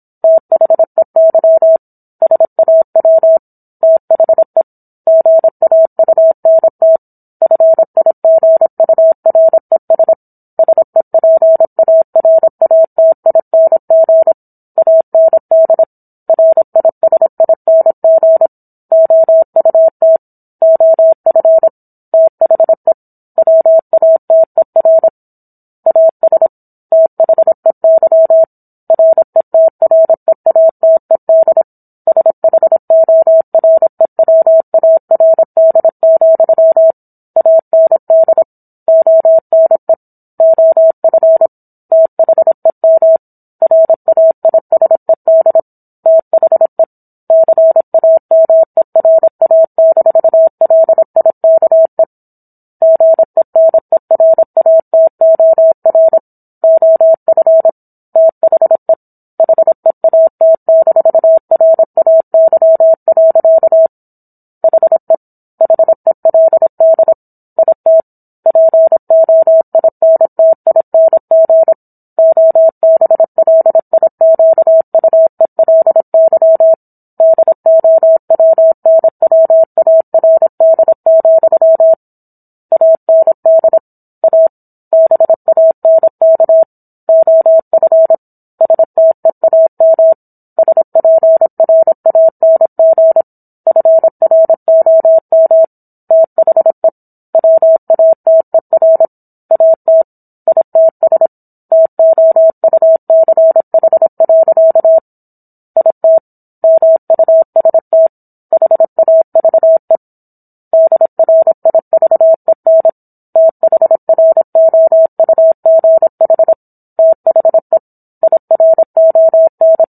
War of the Worlds - 17-Chapter 17 - 26 WPM